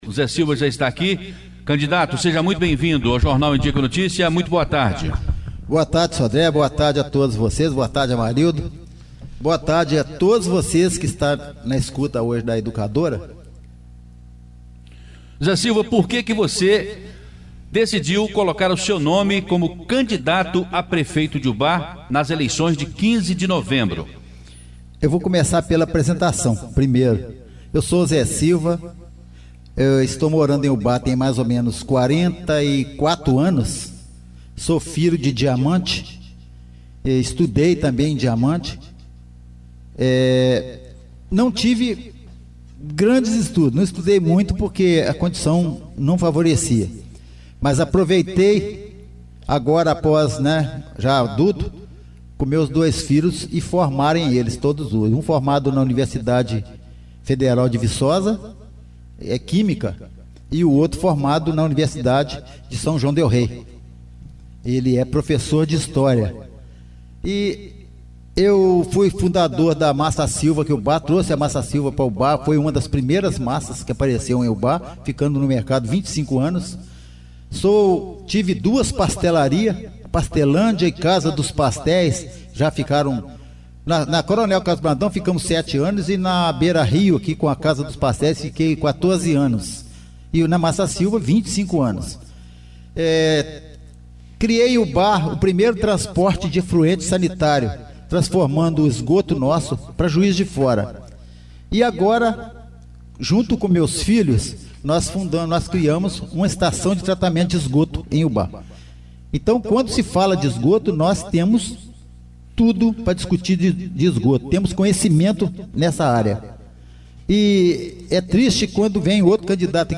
Educadora na boca da urna!Entrevista às 12h30.